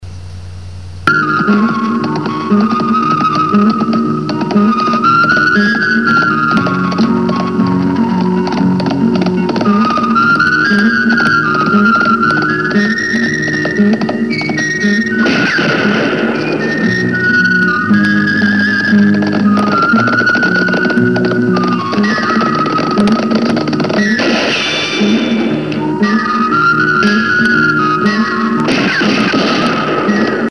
28k 56k 100k _____________ Thème musical